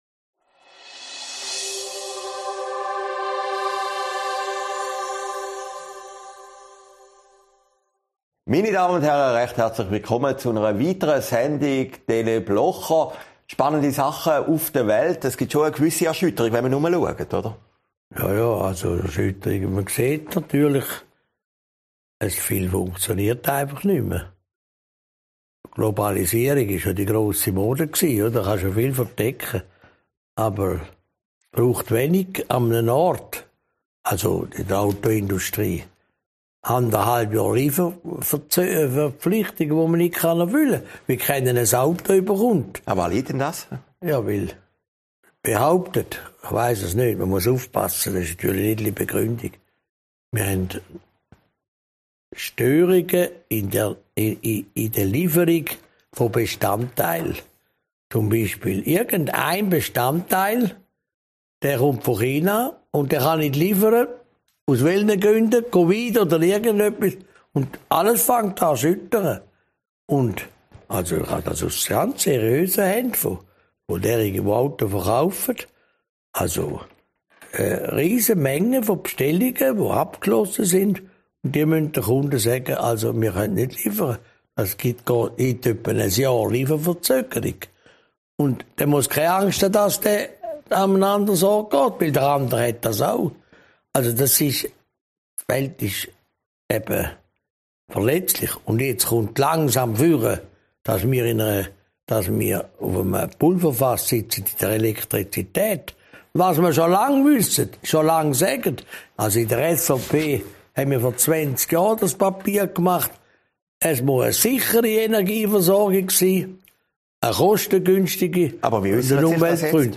Sendung vom 8. Oktober 2021, aufgezeichnet in Herrliberg